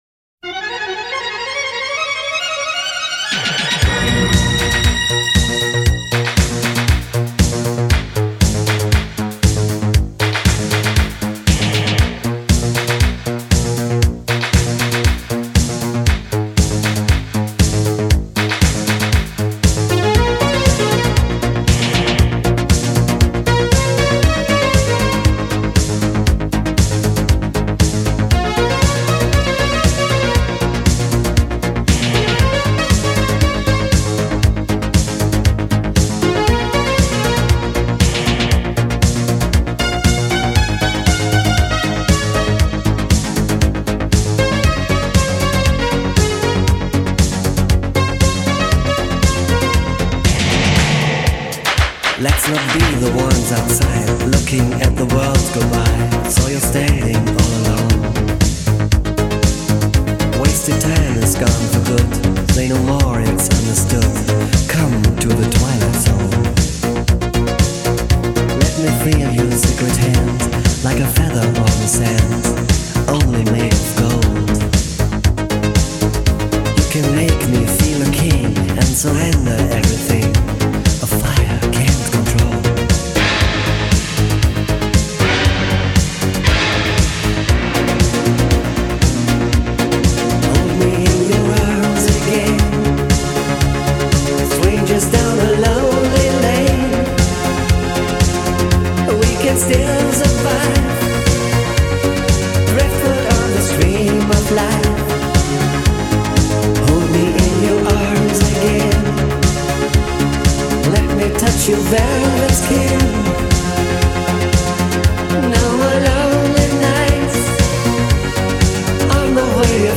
Genre: Electronic
Style: Euro-Disco